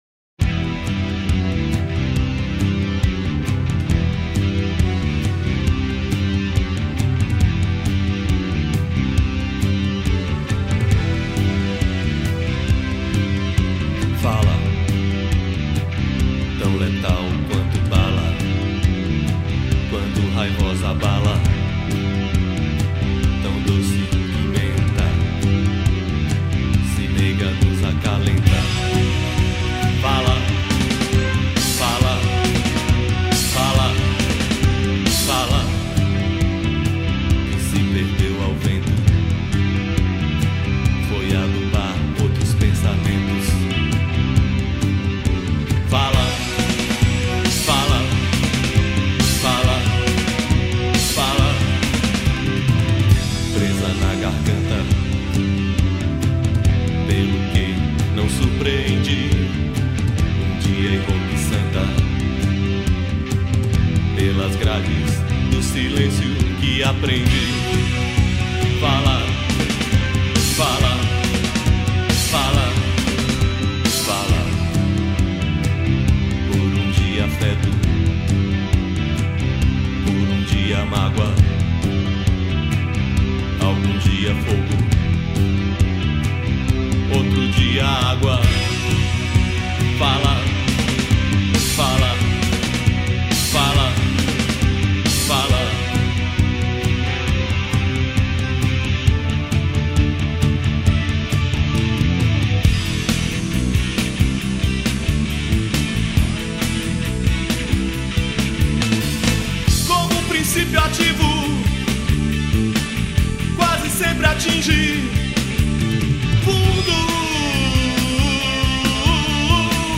Baixo Elétrico 6
Teclados